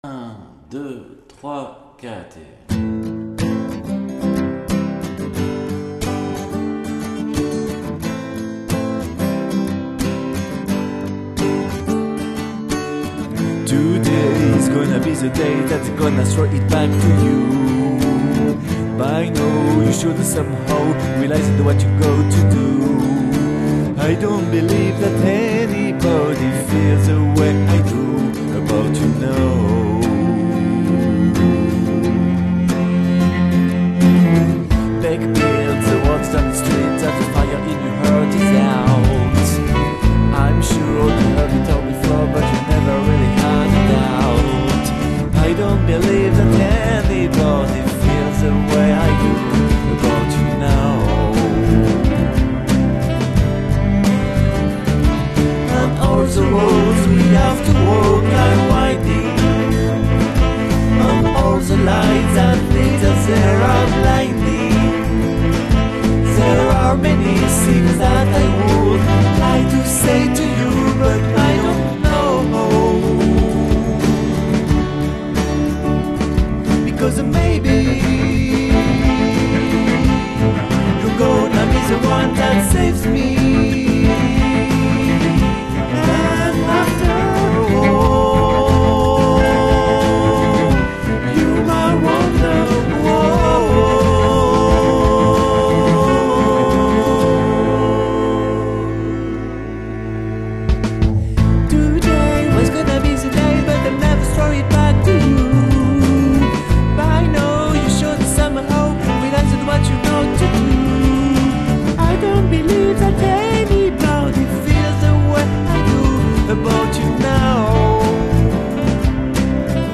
Nouvelle tonalité + sax